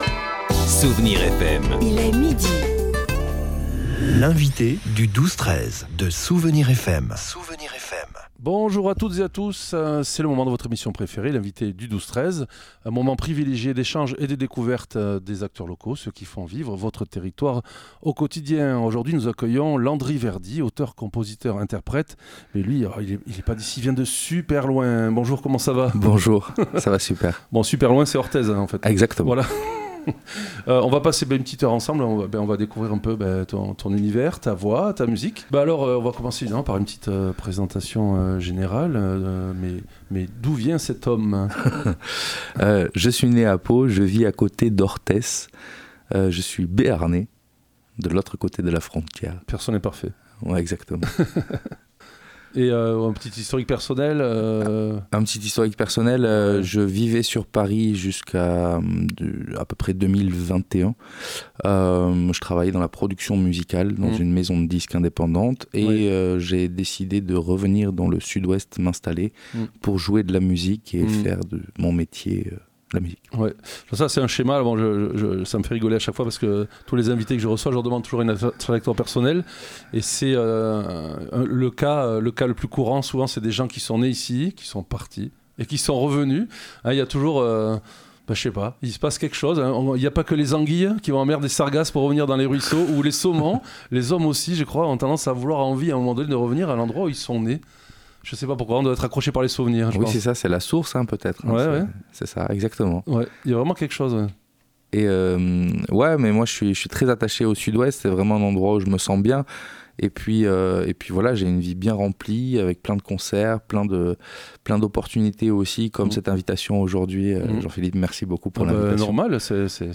Une occasion de découvrir une jolie voix et de belles mélodies en acoustique avec sa guitare classique, entrecoupé de discussions rieuses et chaleureuses ; comme la musique de nôtre invité.